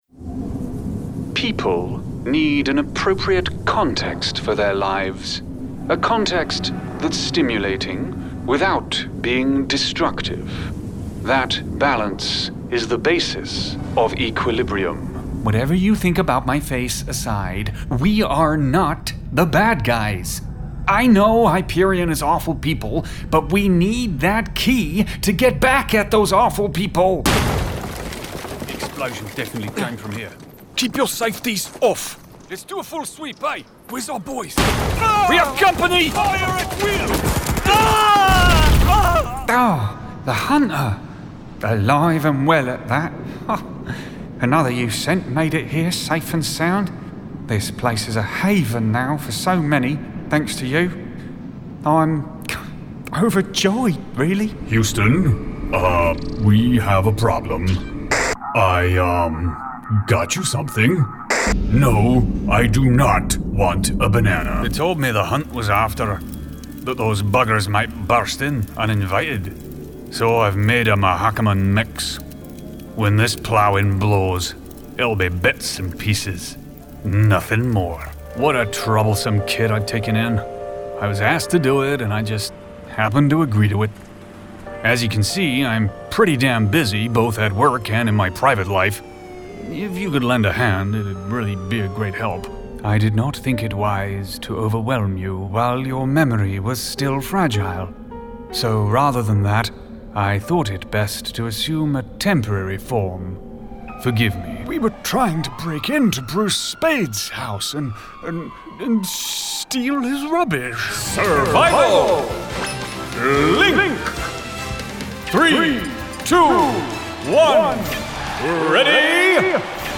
Male / 40s, 50s / English / Gaming, RP, Southern
Showreel